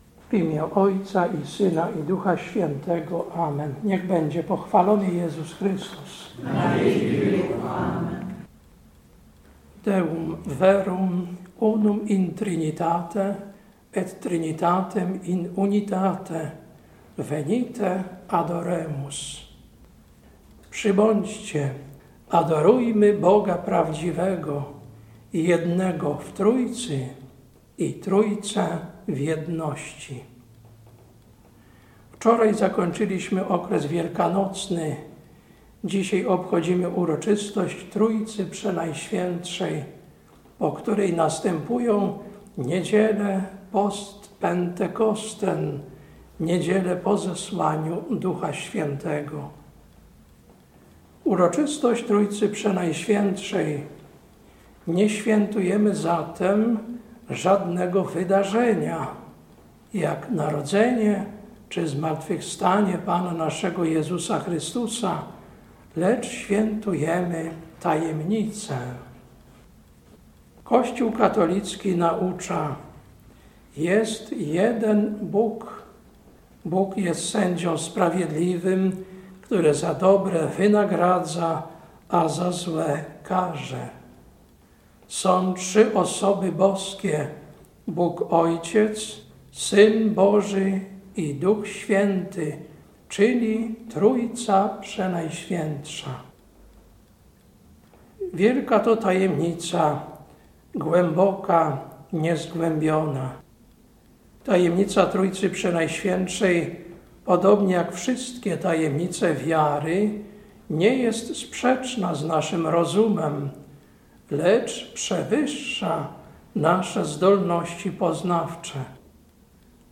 Kazanie na Uroczystość Trójcy Przenajświętszej, 26.05.2024 Lekcja: Rz 11, 33-36 Ewangelia: Mt 28, 18-20